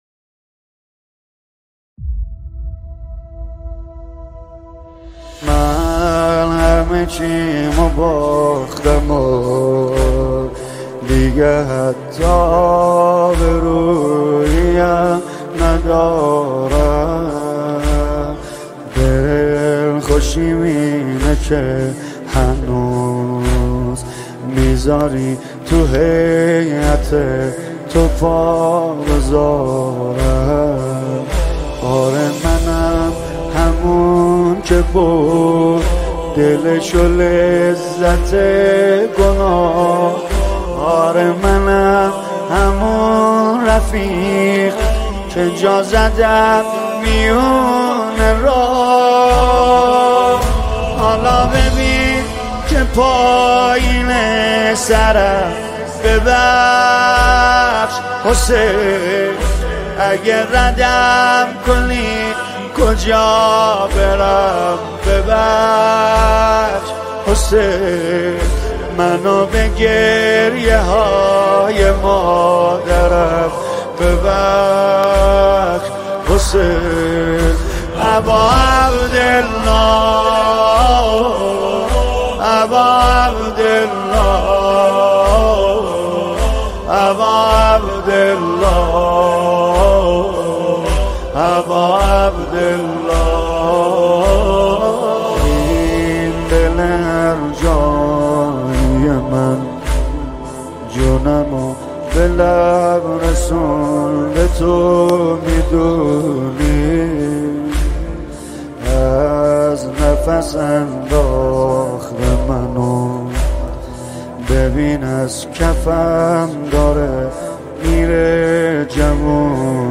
نماهنگ دلنشین